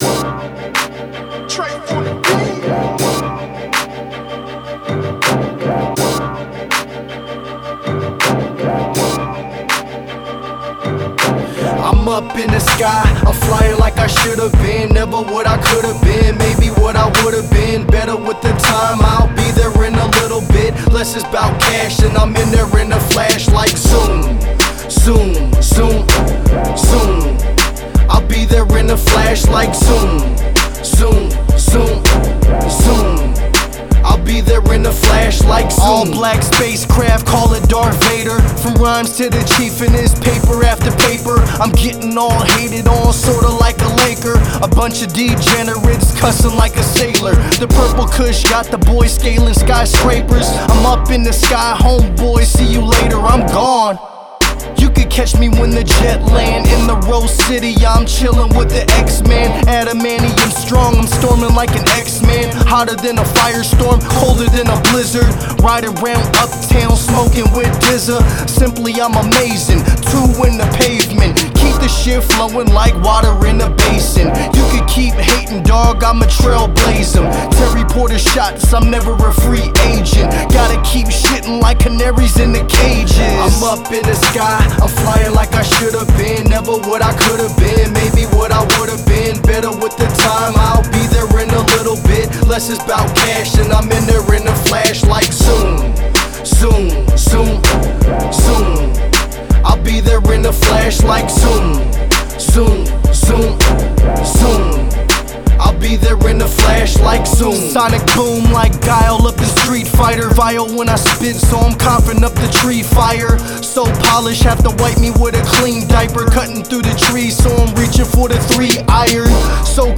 I love rap tracks where the grime overpowers the melody.